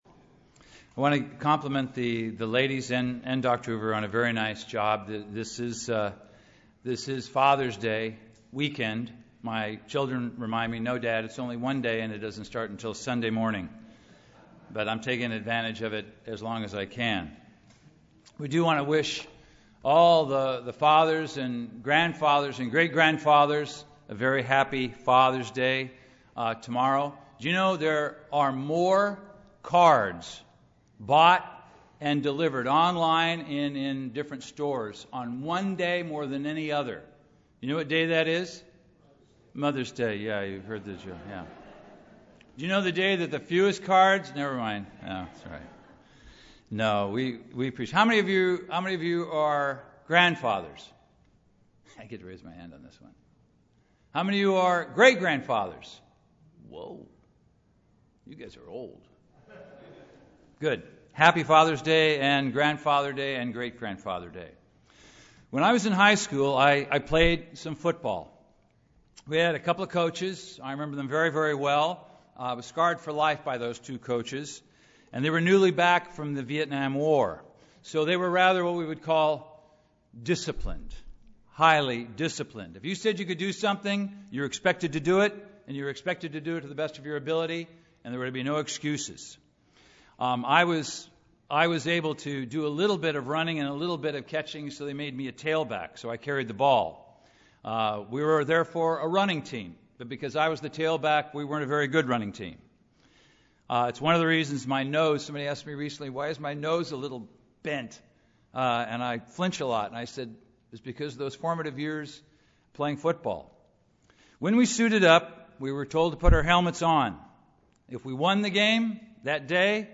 How has our heart changed? This sermon identifies four acts of piety and righteousness that bear spiritual fruit which protects our heart and allows us to grow.
Given in Los Angeles, CA